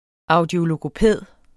Udtale [ ˈɑwdjologoˌpεˀð ] eller [ ɑwdjologoˈpεˀð ]